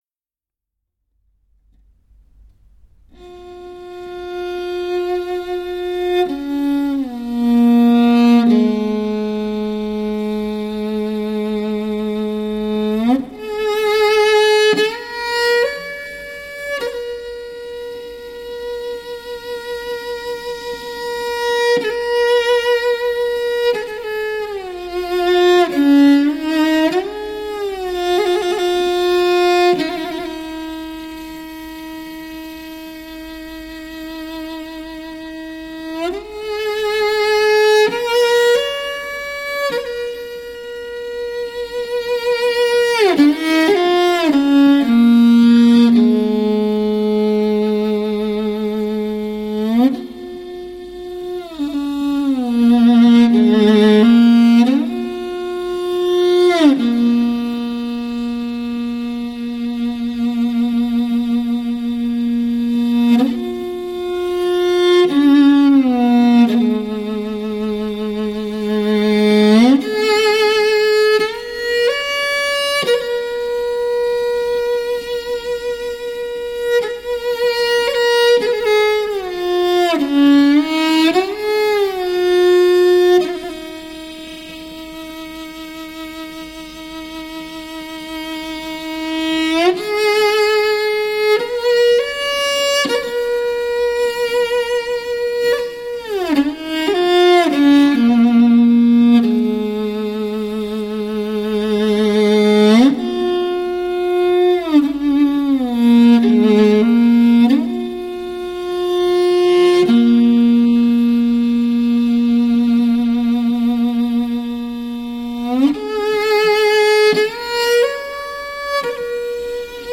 真乐器录音 发烧无伴奏
完美录音 自然混响 频带极宽 声音厚而清晰